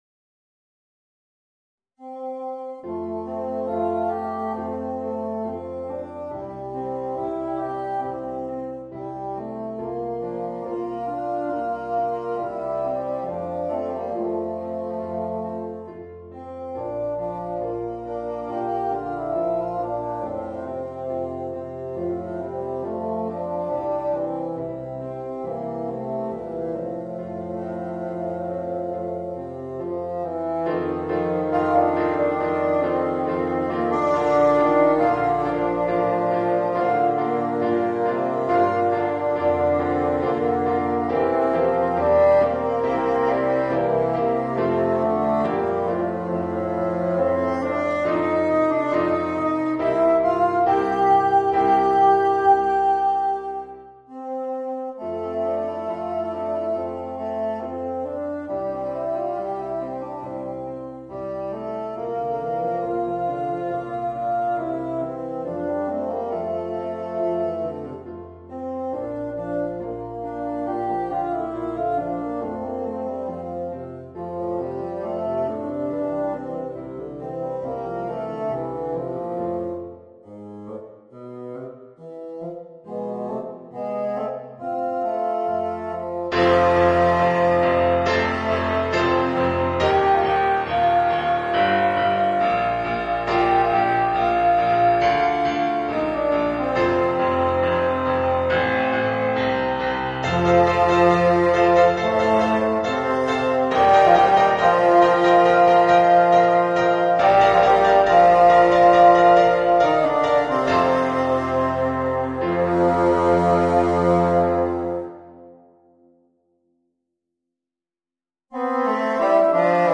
Voicing: 2 Bassoons and Piano